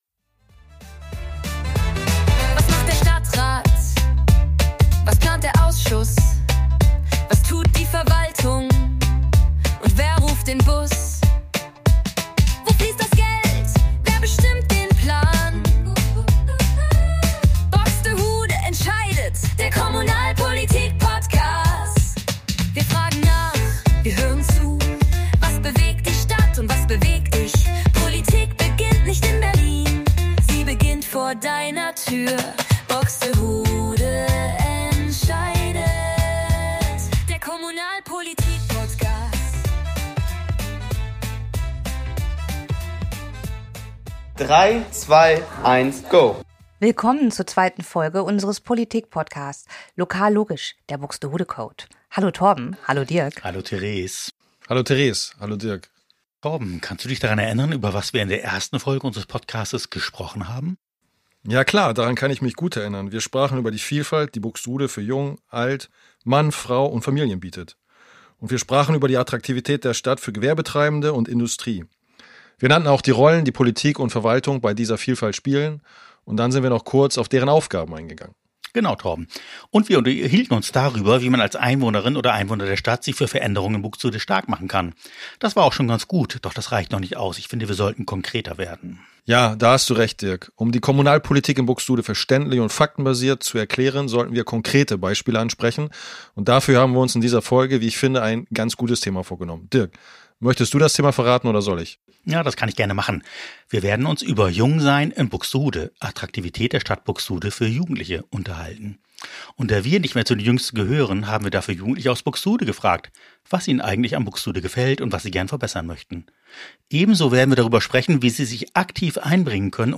Was schätzen Jugendliche an ihrer Stadt – und wo drückt der Schuh? In dieser Folge hören wir direkt hinein: in Schulflure, O-Töne, Gespräche und Ausschusssitzungen.